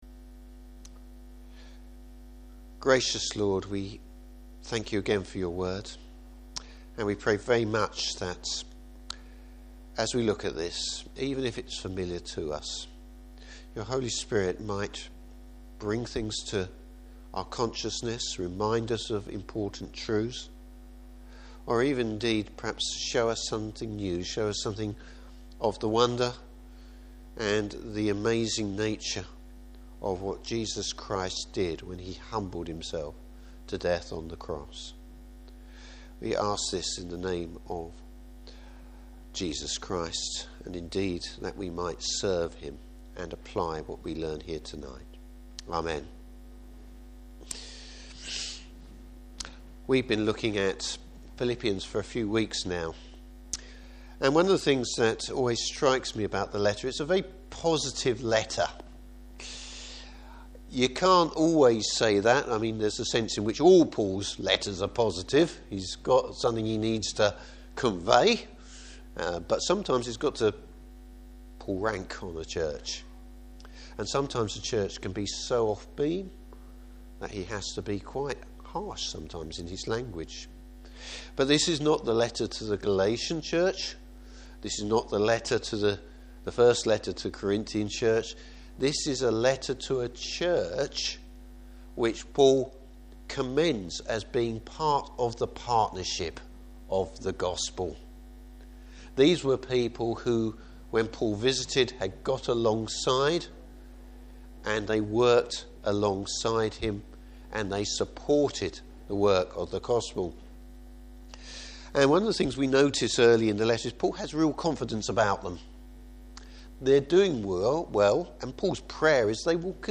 Service Type: Evening Service Jesus, the ultimate example of servanthood.